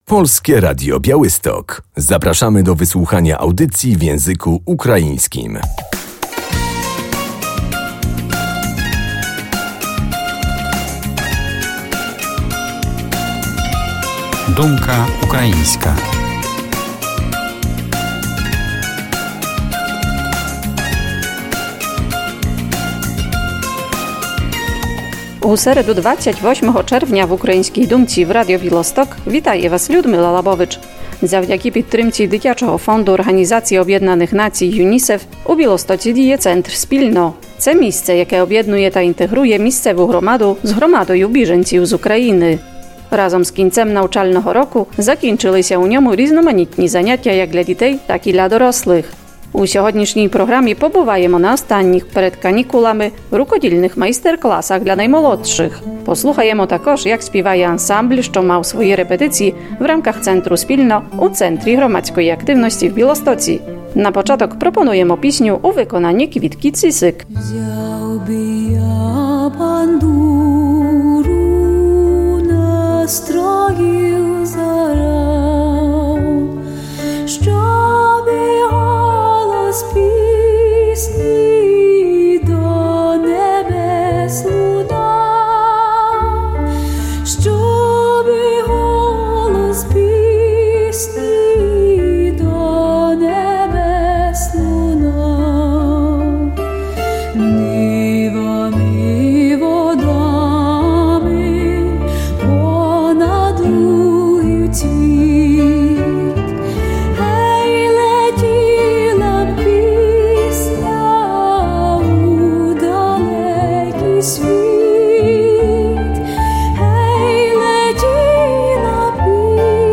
W programie będziemy na zajęciach rękodzielniczych dla dzieci, a także na próbie zespołu dla uchodźców. Spotkania odbywały się w ramach Centrum Spilno UNICEF Białystok.